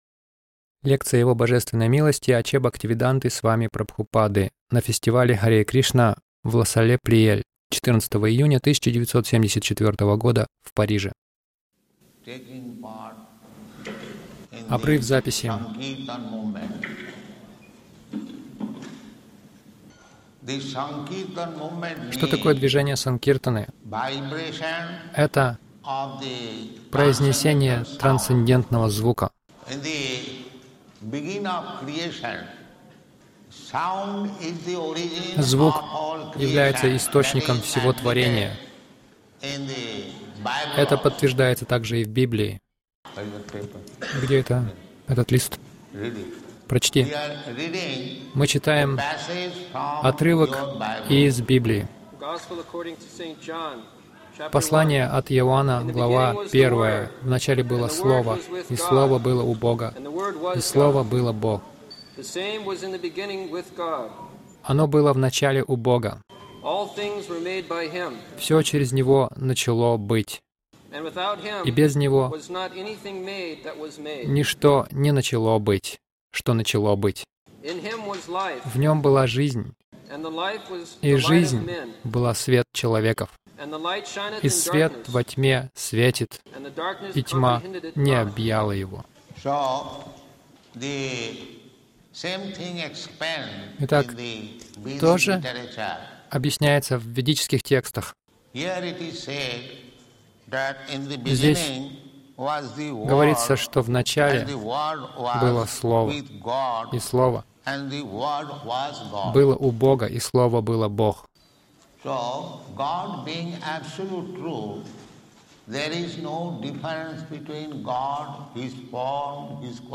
Публичная лекция — Кто знает Бога